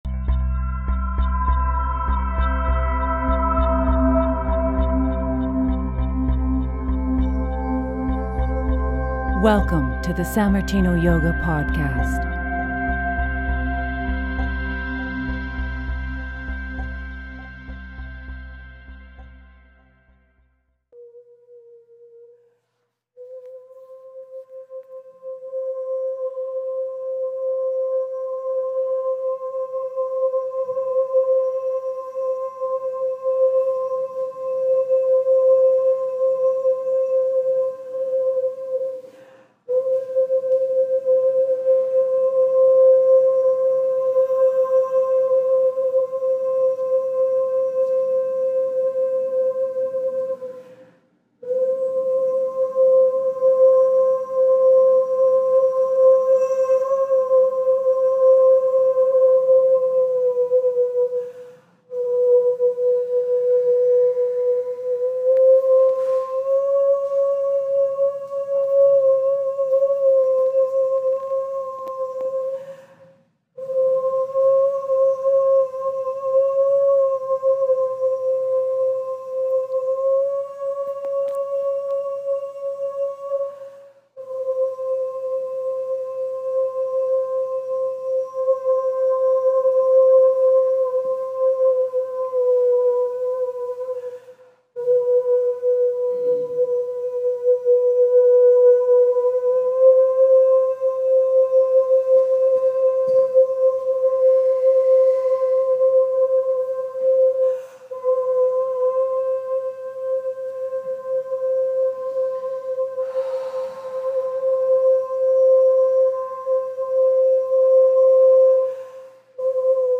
Live Toning Session
at the Vancouver Yoga & Toning Workshop